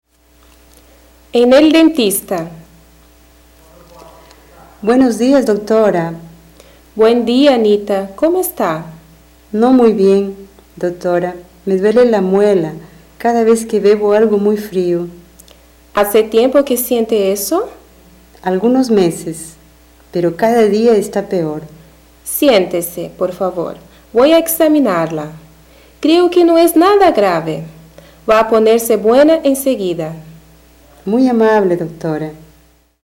Description: Áudio do livro didático Língua Espanhola I, de 2008. Diálogo com palavras referentes as partes do corpo.